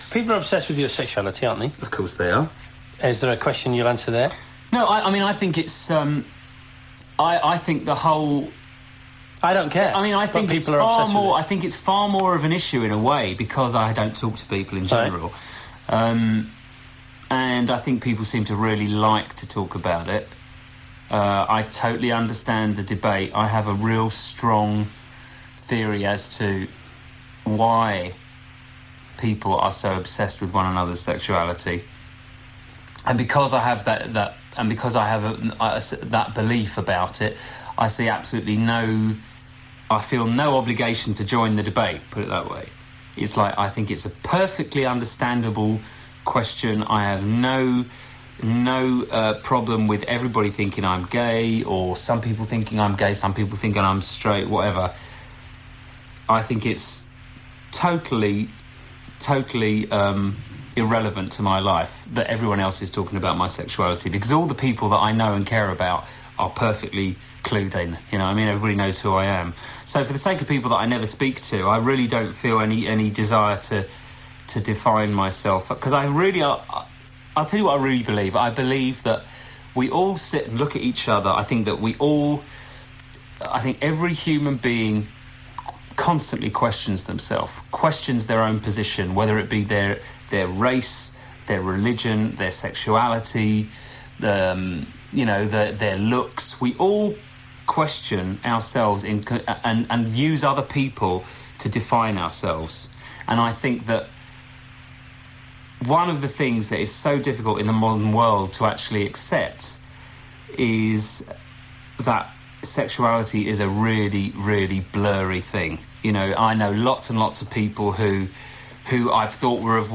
George Michael in conversation with Chris Evans on BBC Radio 1. Broadcast before a concert performance from George on 8 December 1996.